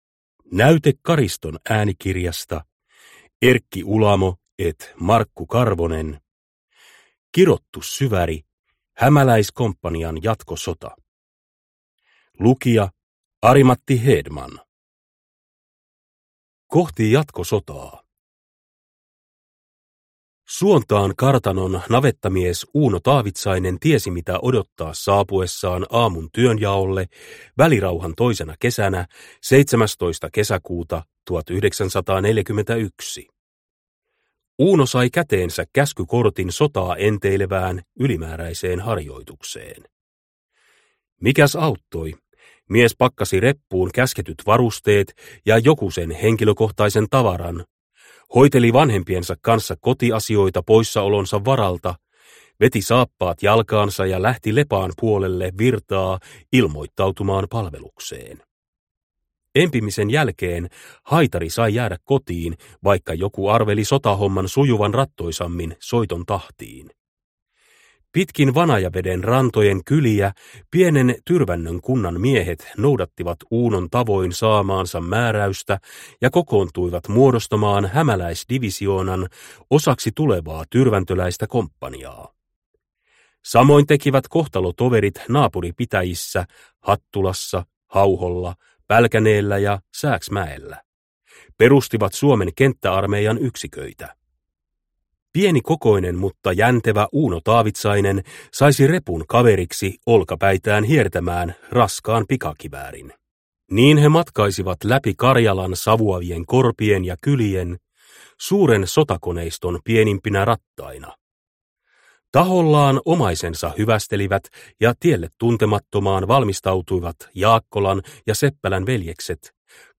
Kirottu Syväri – Ljudbok – Laddas ner